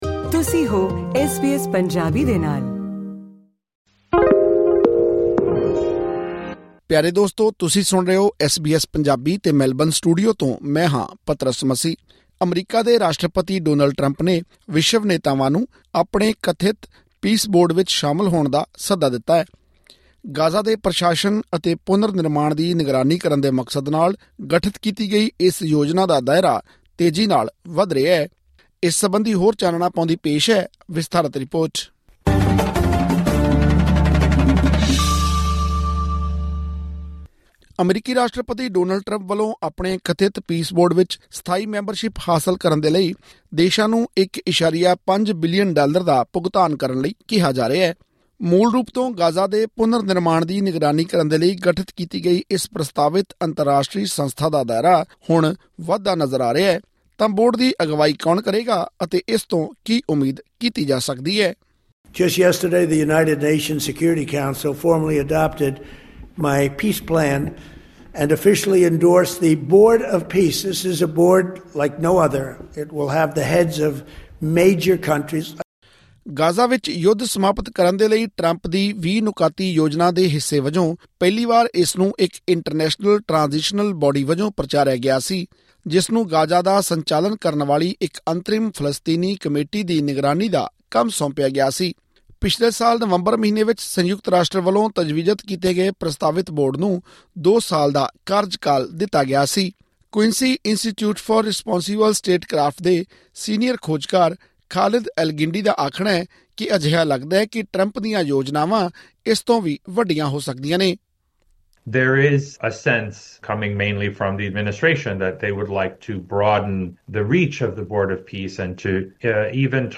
ਆਸਟ੍ਰੇਲੀਆ ਇਸ ਸੱਦੇ ’ਤੇ ਵਿਚਾਰ ਕਰ ਰਿਹਾ ਹੈ, ਜਦਕਿ ਕਈ ਮੁਲਕਾਂ ਵੱਲੋਂ ਘੱਟ ਰੁਚੀ ਅਤੇ ਇਜ਼ਰਾਈਲ ਸਮੇਤ ਆਲੋਚਨਾ ਵੀ ਸਾਹਮਣੇ ਆ ਰਹੀ ਹੈ। ਹੋਰ ਜਾਣਕਾਰੀ ਲਈ ਸੁਣੋ ਇਹ ਆਡੀਓ ਰਿਪੋਰਟ।